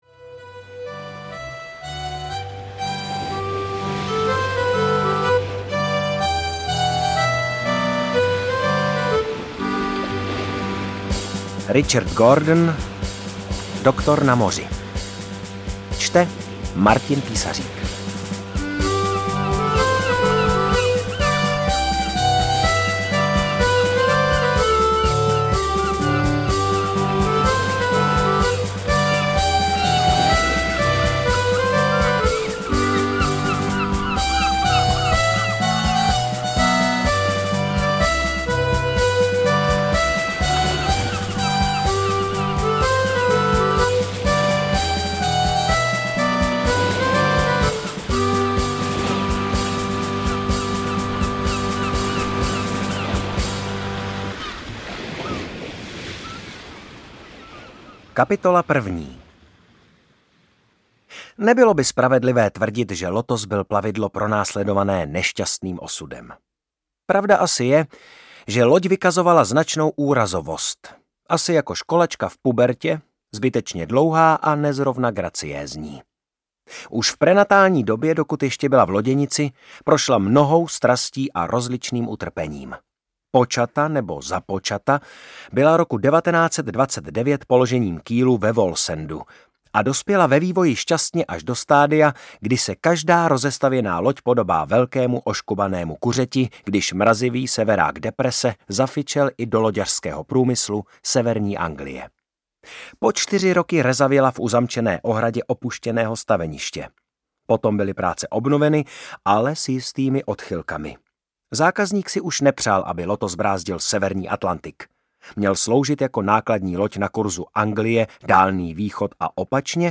Audio kniha
Ukázka z knihy
• InterpretMartin Písařík